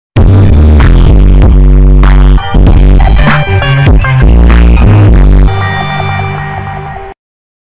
Respect +++ Estourado - Botão de Efeito Sonoro